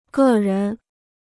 个人 (gè rén): individual; personal.